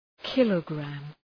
Προφορά
{‘kılə,græm}